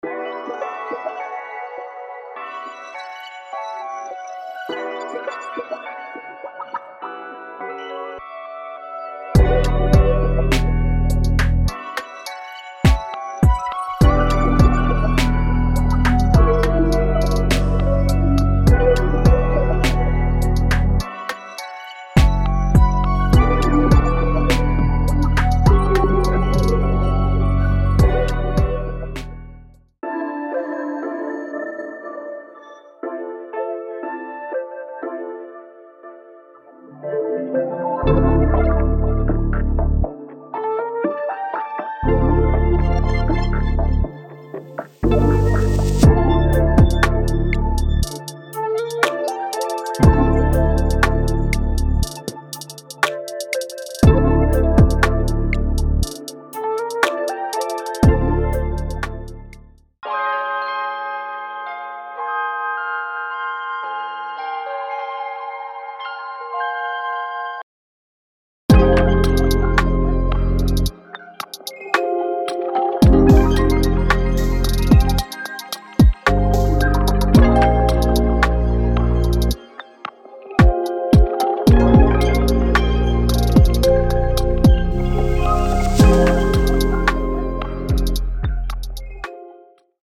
Analog Lab Bank 1 – Classic: A collection of sounds that that capture classic tones and vibes, perfect for starting your melodies ($29.99 Value)
Analog Lab Bank 2 – Multikeys: Flip your classic tone melodies in to deep, textural vibes, perfect for contemporary R&B. Each key strike produces a different tone, full of motion and life ($29.99 Value)
Drum Kit: Turn your golden melodies into full beats that smack. With no recycled sounds anywhere to be seen, you can be sure that your drums will stand out from the crowd, whilst punching through the mix ($29.99 Value)
midas-touch-audio-demo.mp3